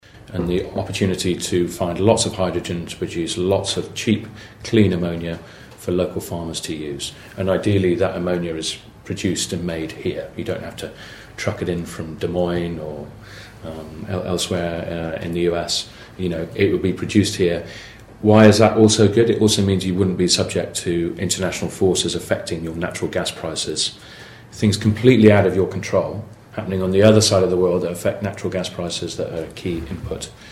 Representatives from Snowfox Discovery, a natural hydrogen exploration company, appeared before the Audubon County Board of Supervisors on Tuesday afternoon to outline their plans.